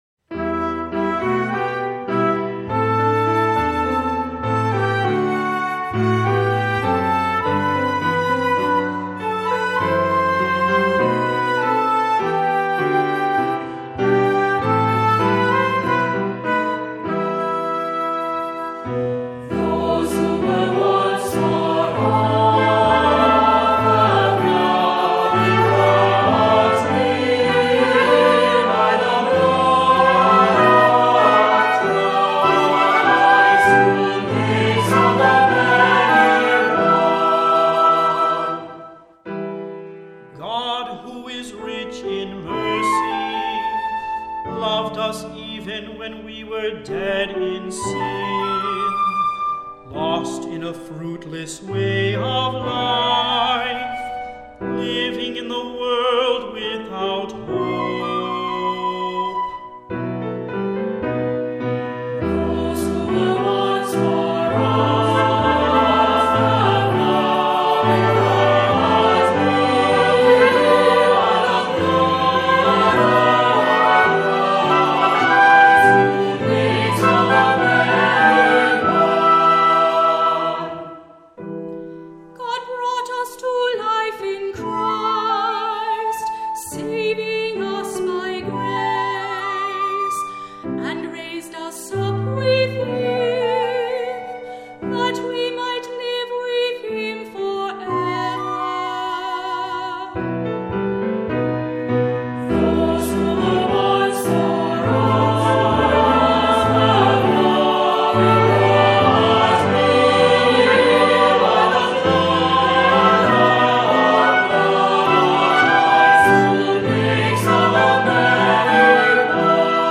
Voicing: Two-part mixed choir; Cantor; Assembly